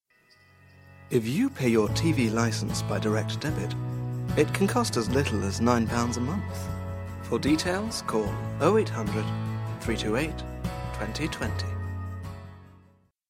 Native British, radio, video game experience
englisch (uk)
Sprechprobe: Werbung (Muttersprache):
I am a British actor, RP, experienced in voice over.